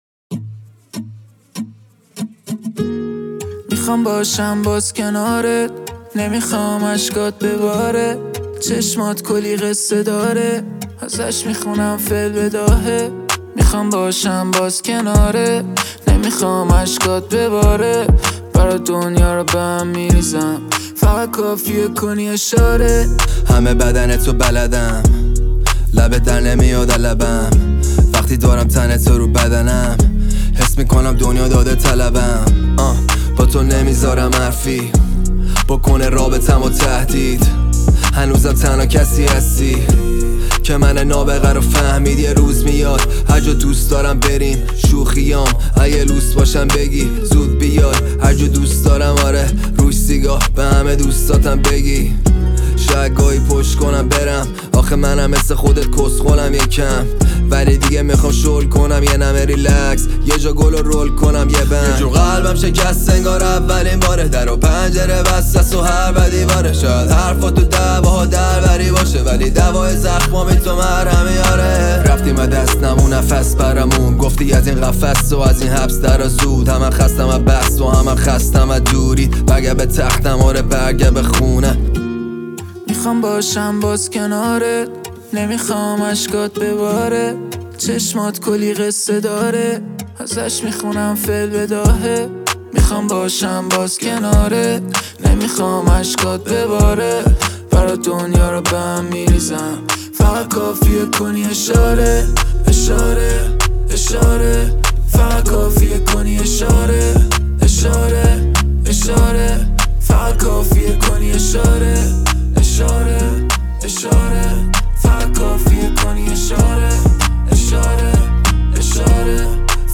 • موسیقی رپ ایرانی